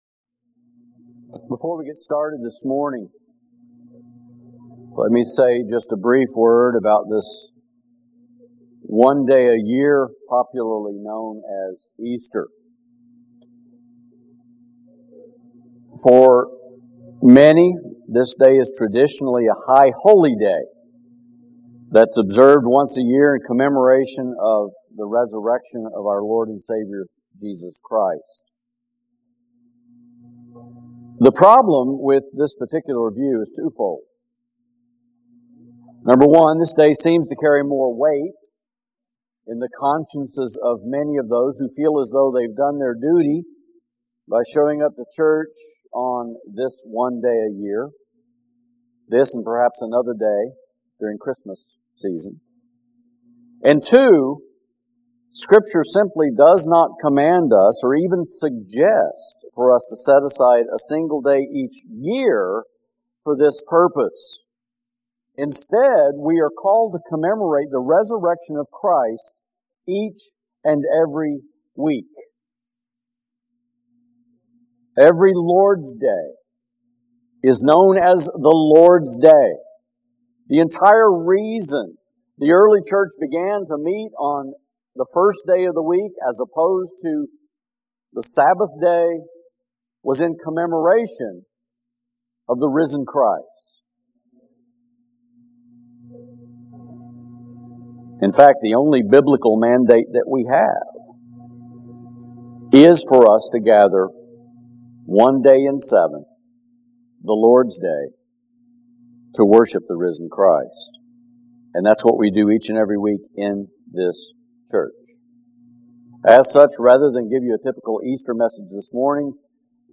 Sermons | Grace Baptist Church San Antonio